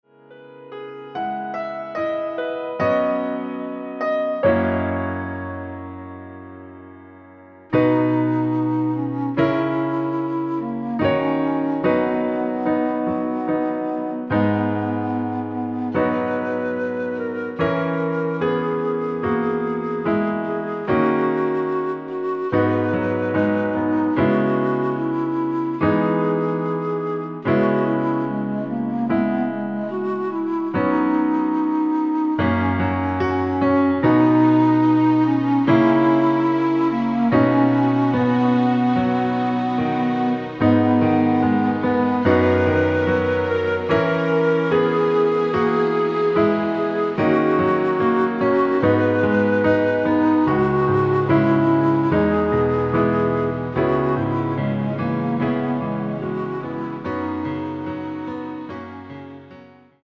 피아노와 스트링만으로 편곡한 MR 입니다. (-1) 내린 멜로디 포함된 MR 입니다.(미리듣기 참조)
◈ 곡명 옆 (-1)은 반음 내림, (+1)은 반음 올림 입니다.
앞부분30초, 뒷부분30초씩 편집해서 올려 드리고 있습니다.